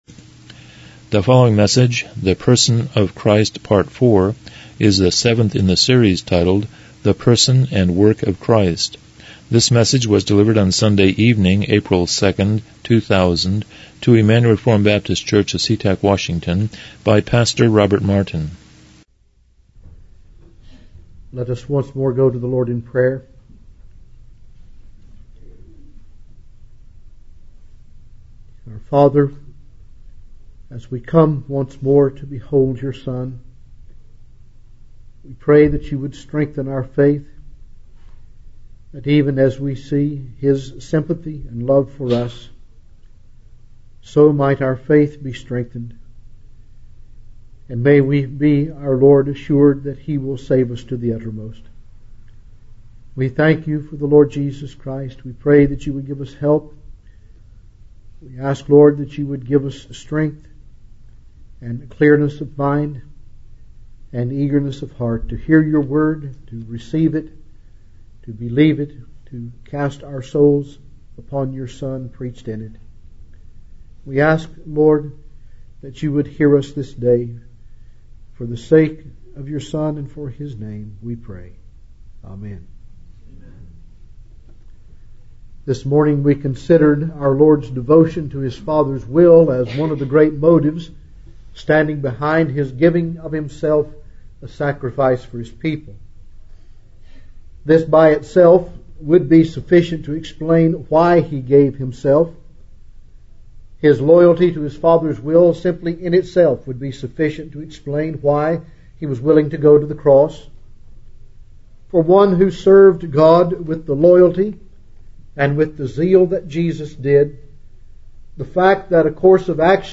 The Person and Work of Christ Service Type: Evening Worship « 06 The Person of Christ #3 08 The Work of Christ #1